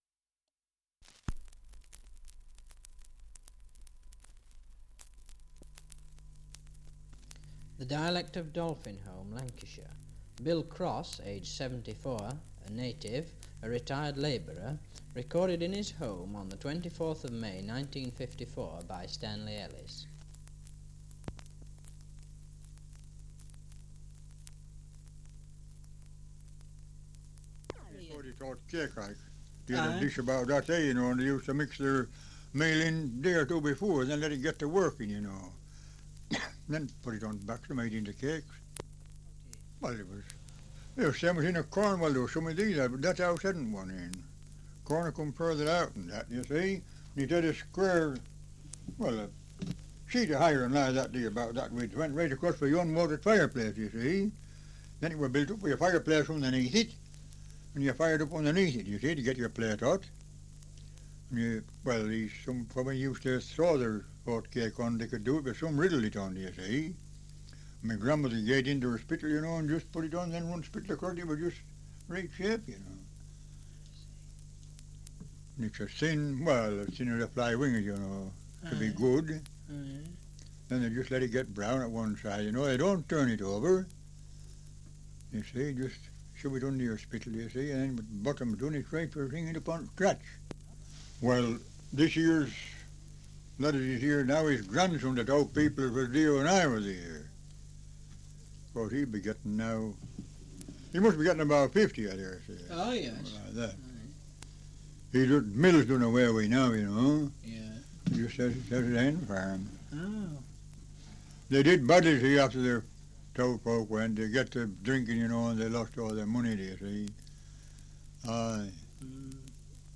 Survey of English Dialects recording in Dolphinholme, Lancashire
78 r.p.m., cellulose nitrate on aluminium